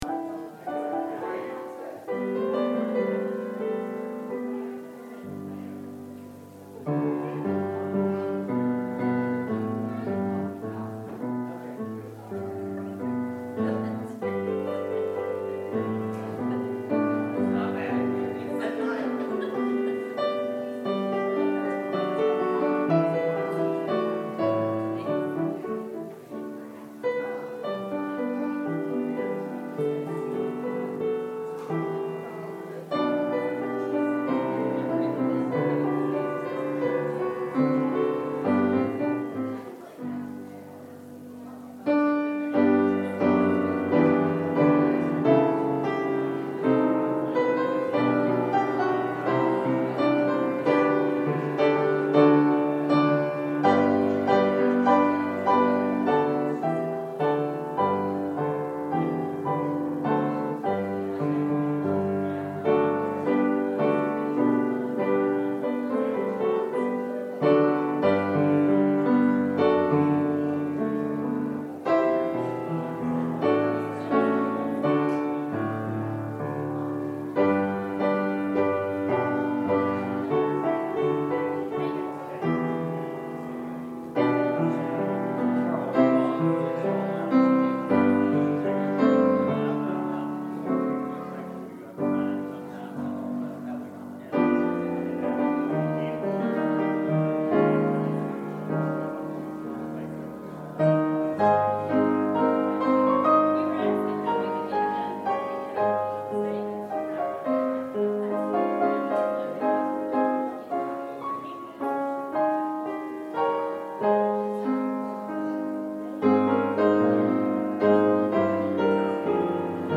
Minister